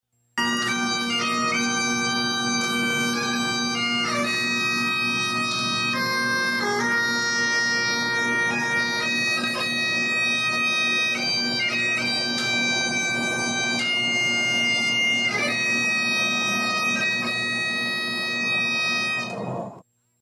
The ringing of the bell signaled the end of duties for those who were called home.  Saturday’s ceremony also included Taps, and music from a bagpipe honoring the fallen….